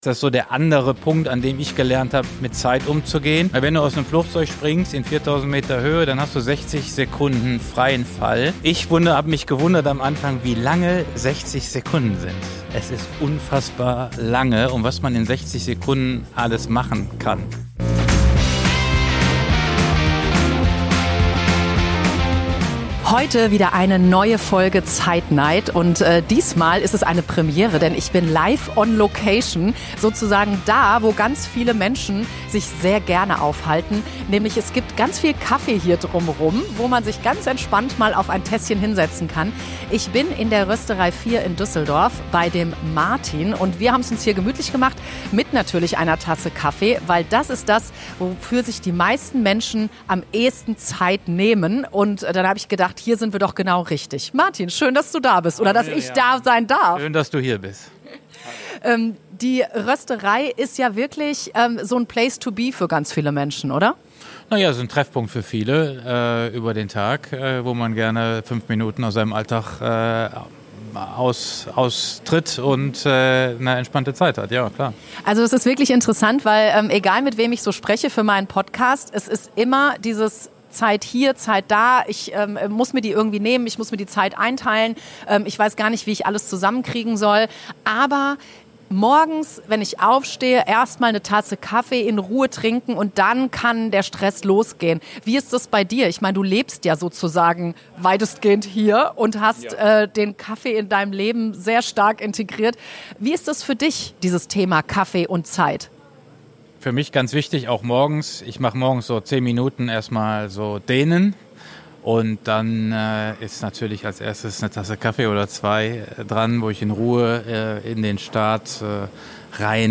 Beschreibung vor 4 Monaten Ein Gespräch über Kaffee – und über Zeit.